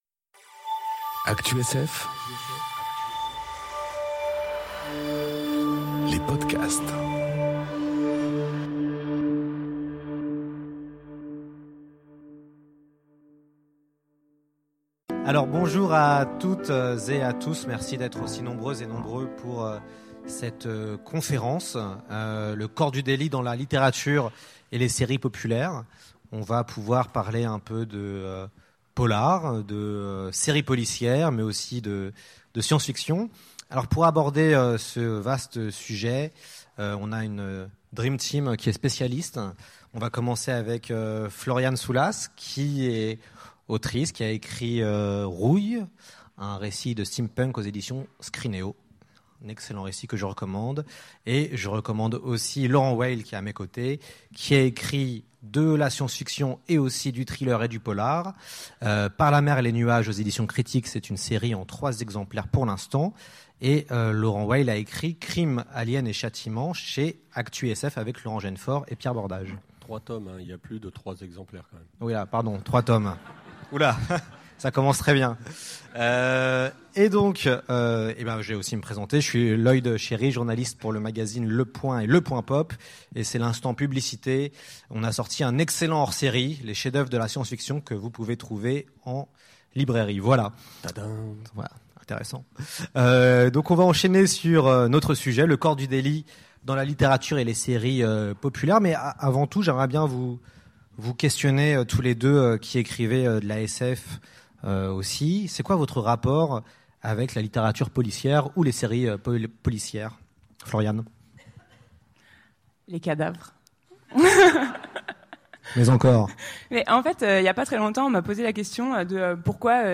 Conférence Le corps du délit dans la littérature et les séries populaires enregistrée aux Utopiales 2018